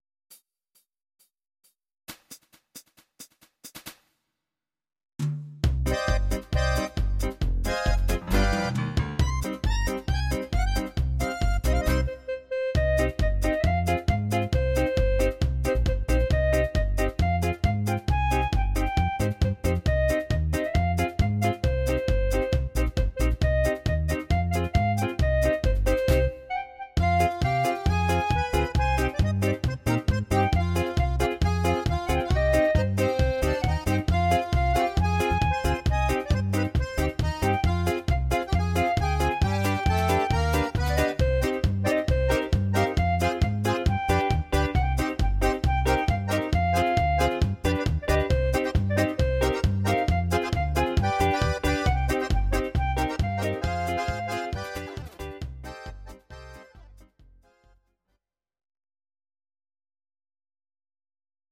Audio Recordings based on Midi-files
Pop, Dutch, 1990s